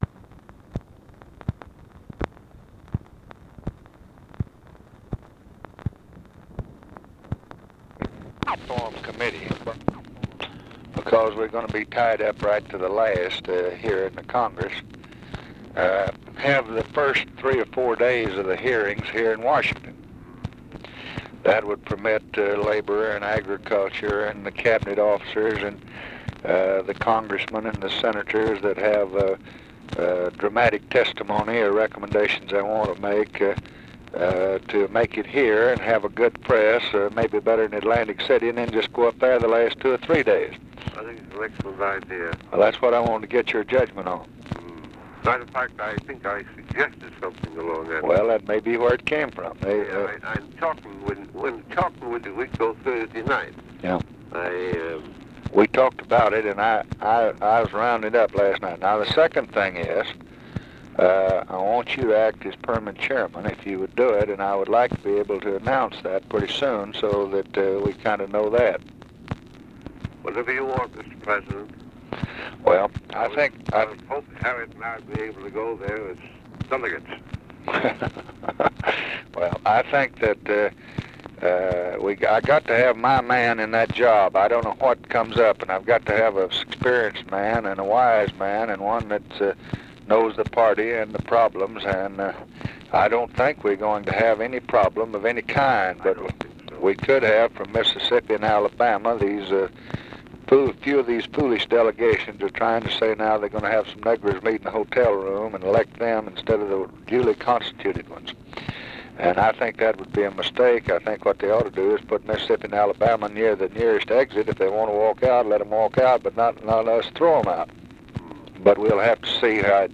Conversation with JOHN MCCORMACK, July 14, 1964
Secret White House Tapes